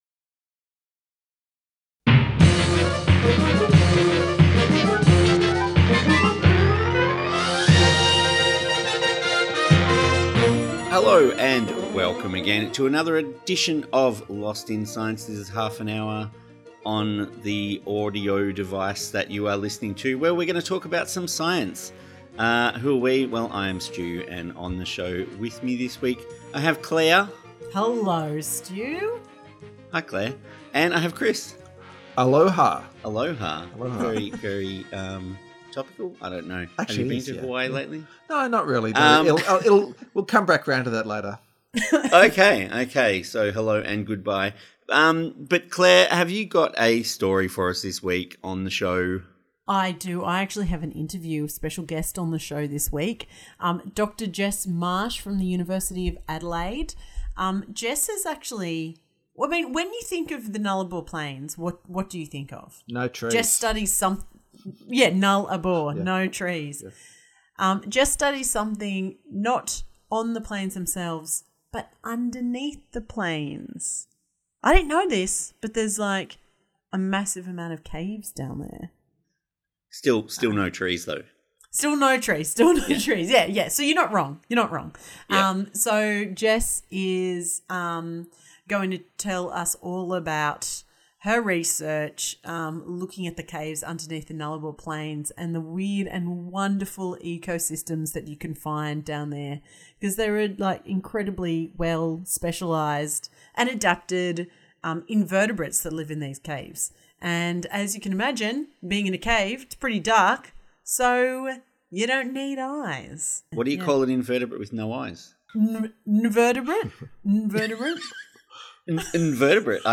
Tweet Lost in Science Thursday 8:30am to 9:00am Entertaining news and discussion about research that has impact on society and providing a wide range of science and technology news.